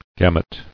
[gam·ut]